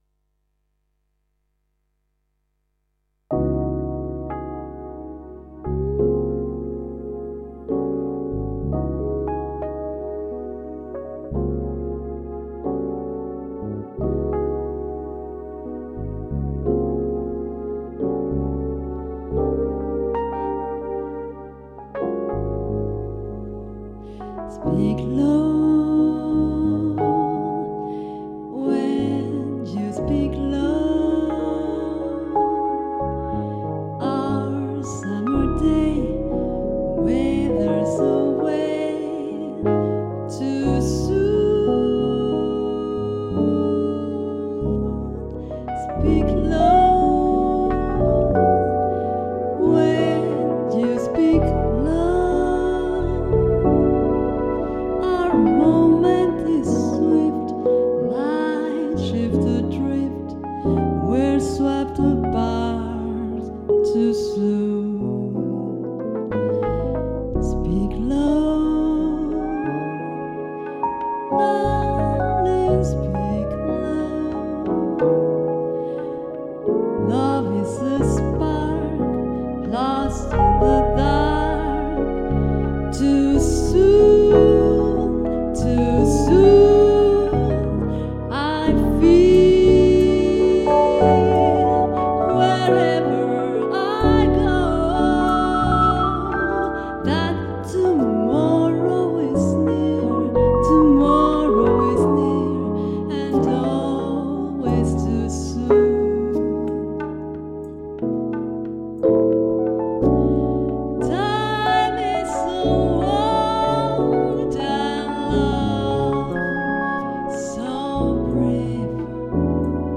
Chanteuse